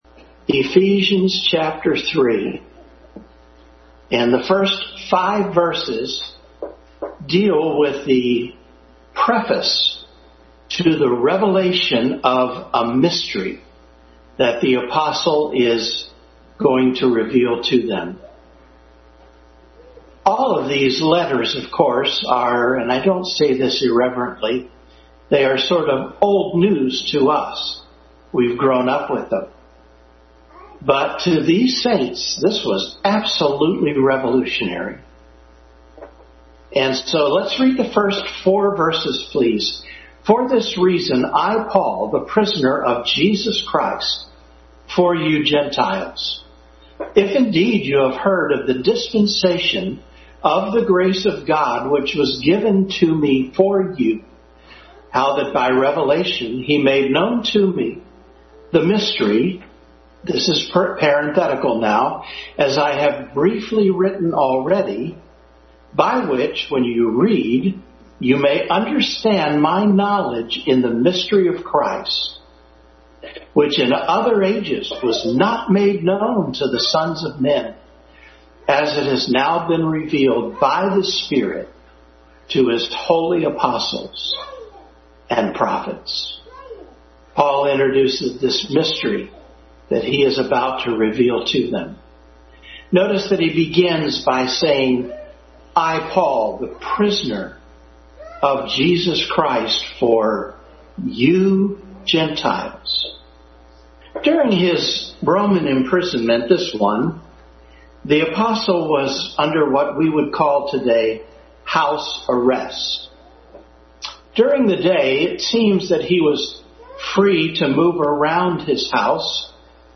Family Bible Hour Message, continued study in Ephesians.